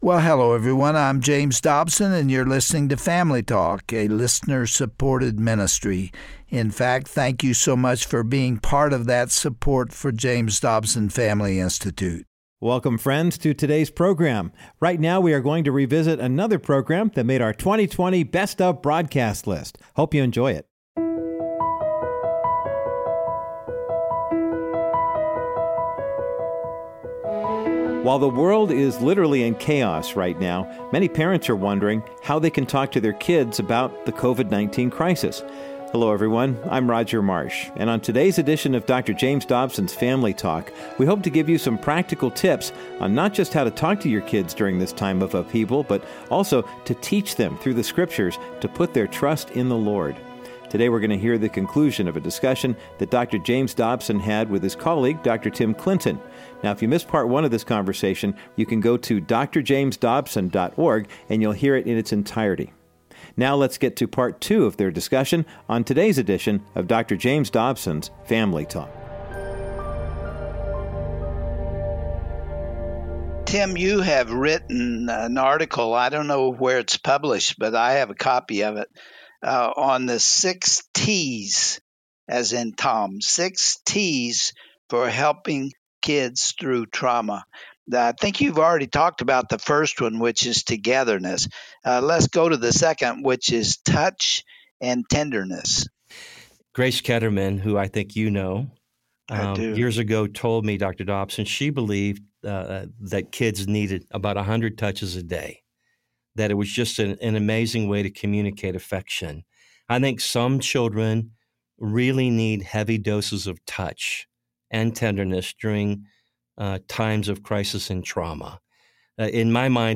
The two men walk through 6 critical ways that moms and dads can help their kids process this crisis.
Order a CD of this Broadcast